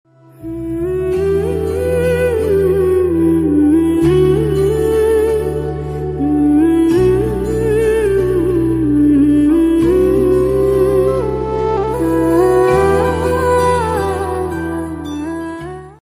romantic instrumental version